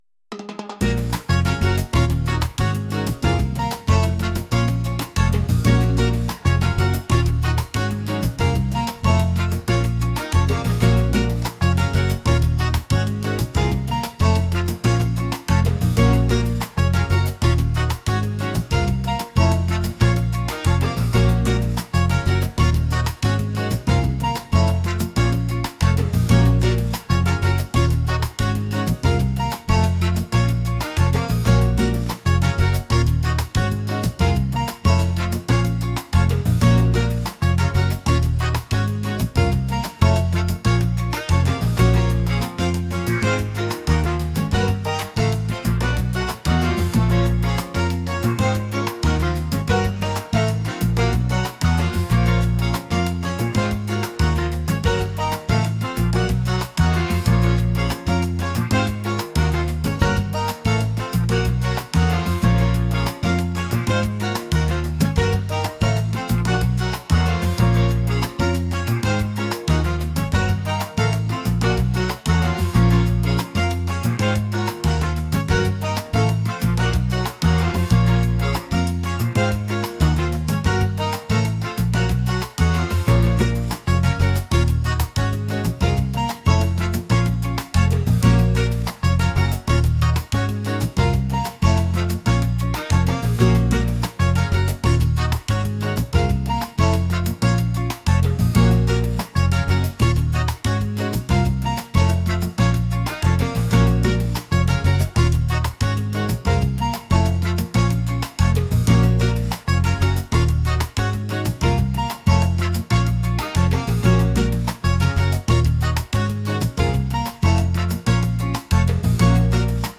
energetic | latin